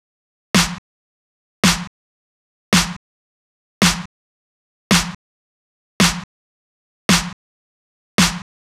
30 Snare.wav